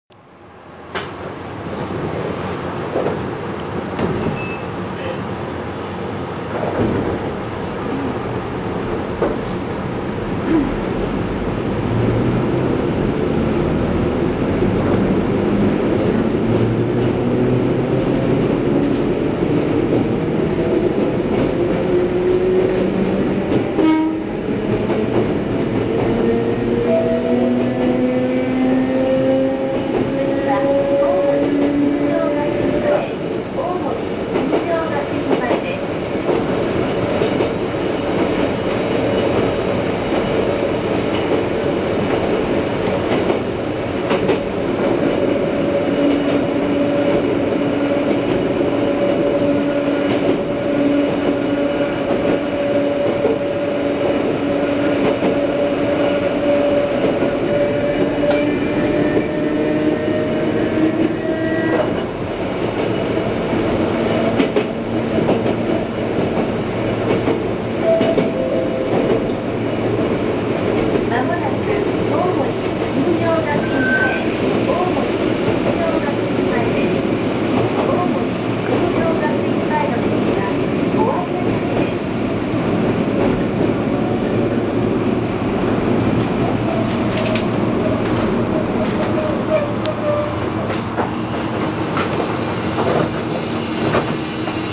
6750系走行音（収録は全て6754Fにて）
【瀬戸線】喜多山〜大森・金城学院前（1分34秒：742KB）
瀬戸線の車両は全て車内自動放送を完備していますが、6750系の車内ではその放送はほとんど聞こえません。
6750_Kitayama-Omori.mp3